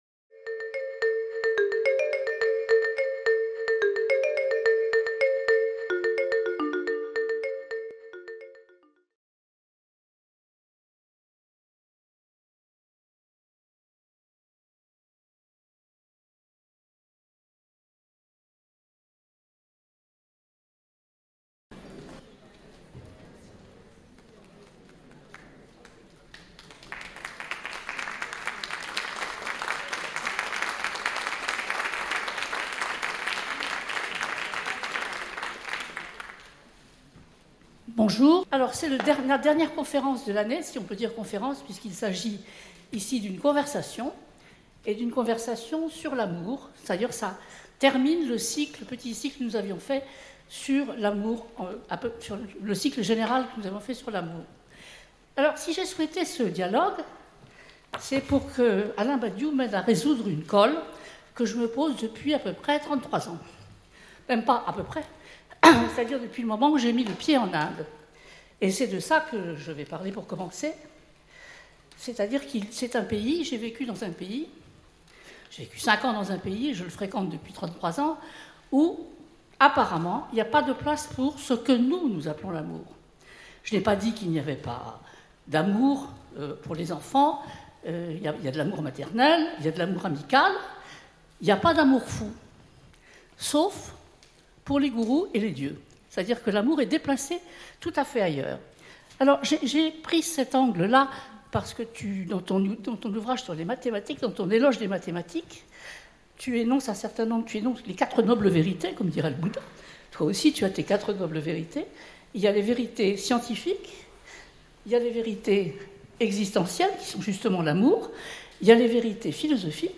Conférence de l’Université populaire du quai Branly (UPQB), donnée le 18 mai 2016 Le cycle Décalages confronte pour la dernière année les points de vue sur les différentes façons de s’aimer selon les époques, les genres et les cultures, qu'il s'agisse d'érotisme, de formes d'unions diverses ou de littérature.